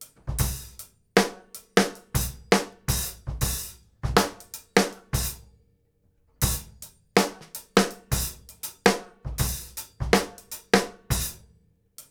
GROOVE 7 06R.wav